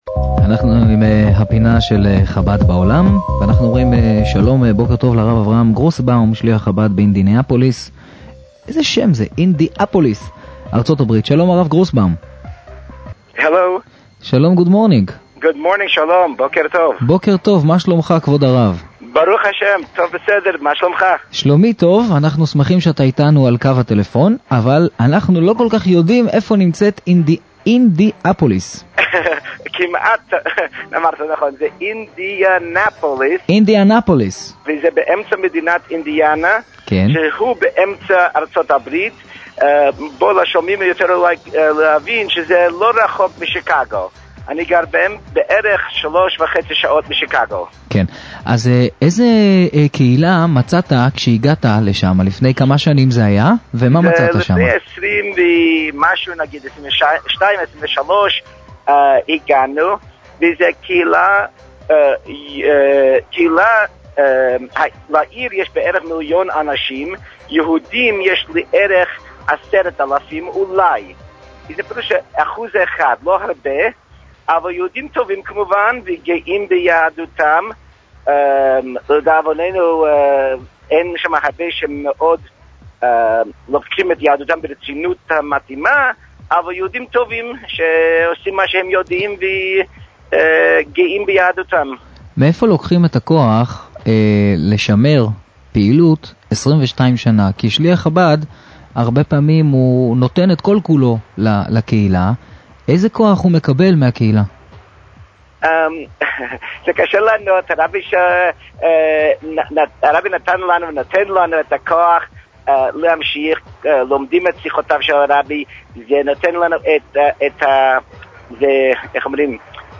היה אורח הפינה הקבועה של ראיון עם שליח חב"ד